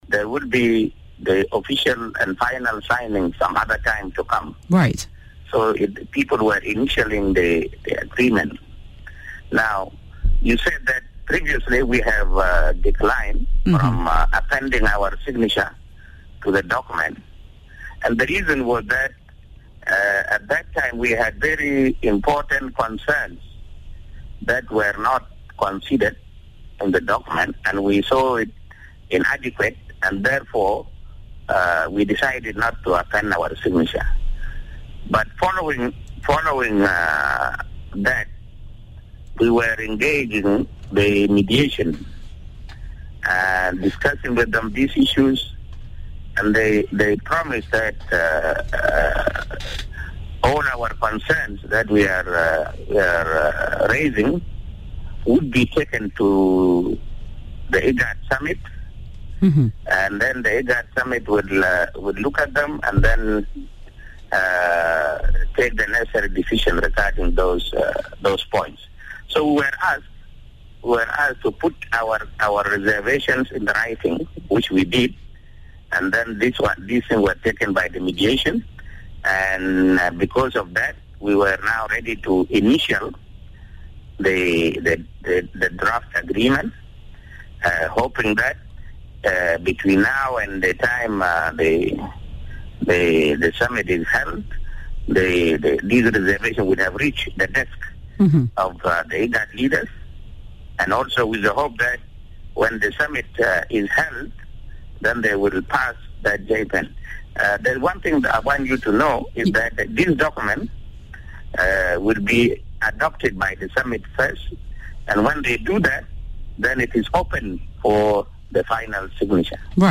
Interview with Dr Lam Akol on initialed draft Peace Deal